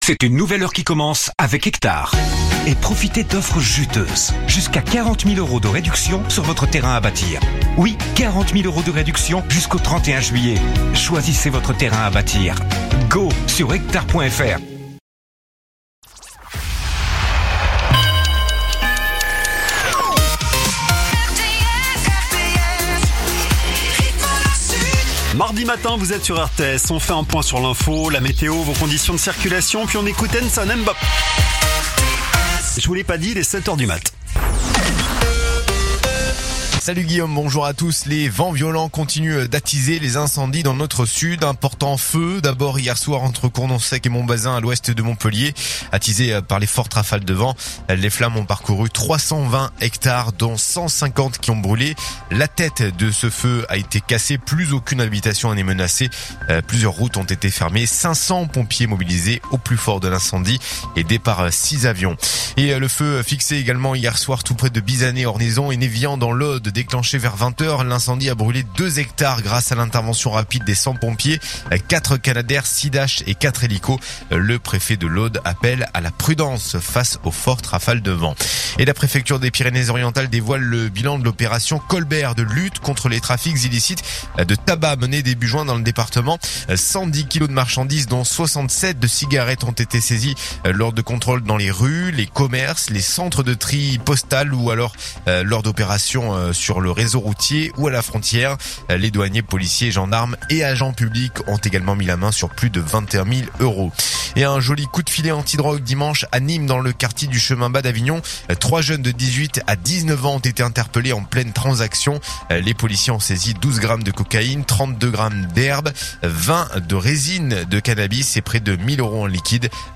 info_mtp_sete_beziers_459.mp3